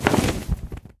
Minecraft Version Minecraft Version latest Latest Release | Latest Snapshot latest / assets / minecraft / sounds / mob / enderdragon / wings5.ogg Compare With Compare With Latest Release | Latest Snapshot
wings5.ogg